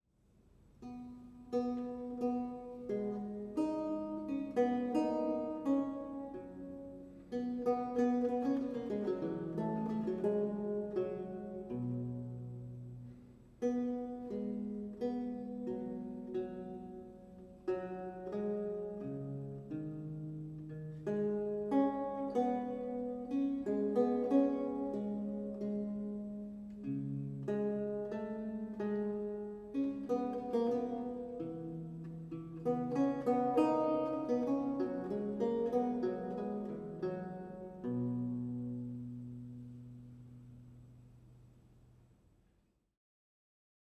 Audio recording of a lute piece from the E-LAUTE project
a 16th century lute music piece originally notated in lute tablature